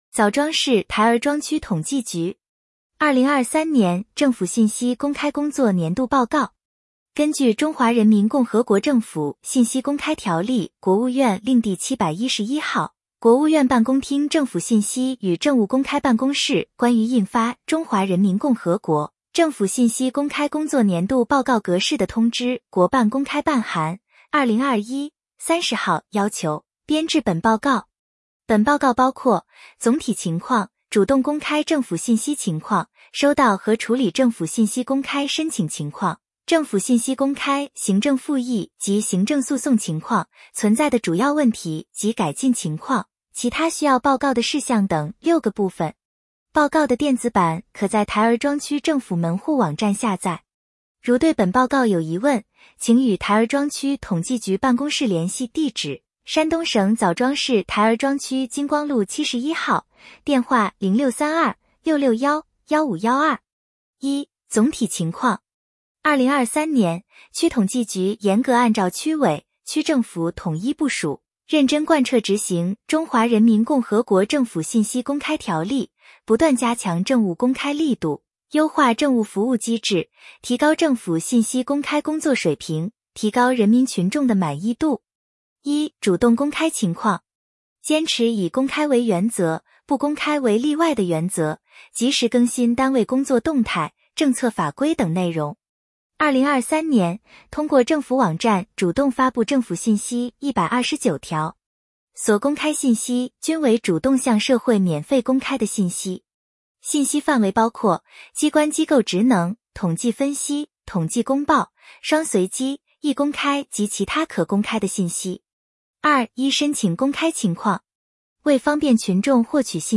点击接收年报语音朗读 枣庄市台儿庄区统计局2023年政府信息公开工作年度报告 作者： 来自： 时间：2024-01-15 根据《中华人民共和国政府信息公开条例》（国务院令第 711 号）、《国务院办公厅政府信息与政务公开办公室关于印发＜中华人民共和国政府信息公开工作年度报告格式＞的通知》（国办公开办函〔 2021 〕 30 号）要求，编制本报告。